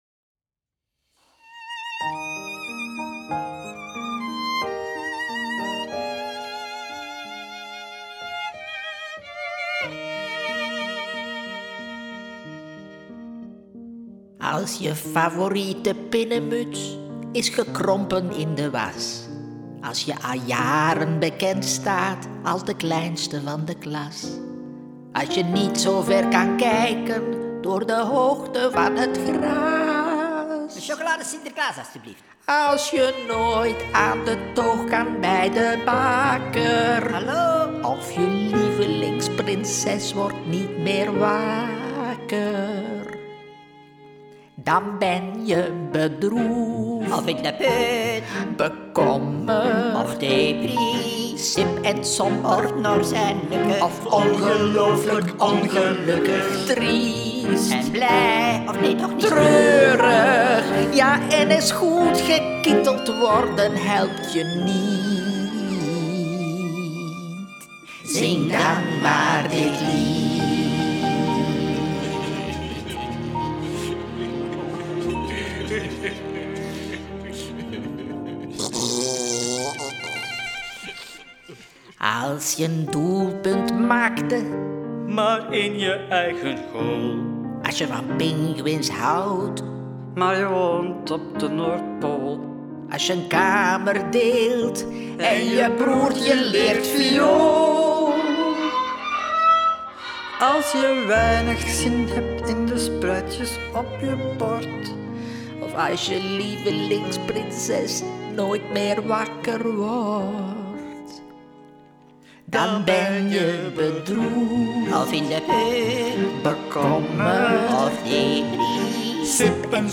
Klarinet en Basklarinet
Viool